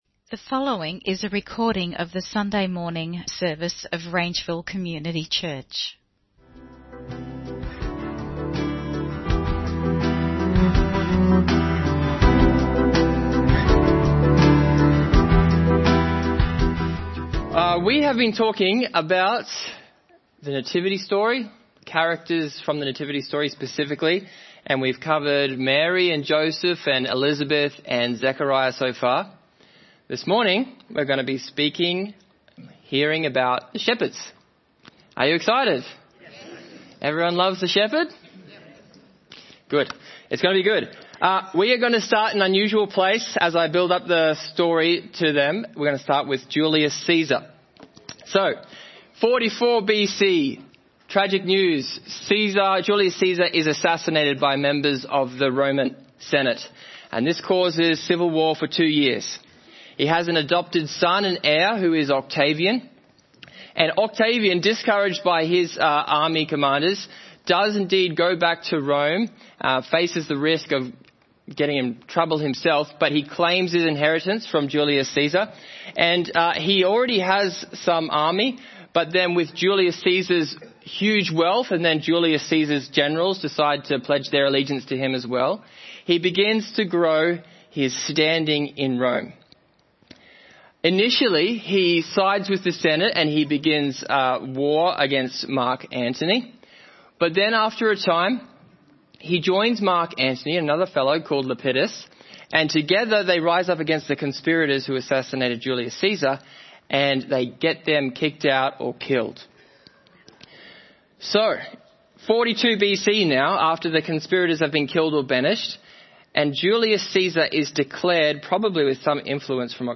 The Christmas Gift for the Shepherds (Sermon Only - Video + Audio)